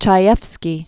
(chī-ĕfskē, chā-), Sidney Aaron Known as "Paddy." 1923-1981.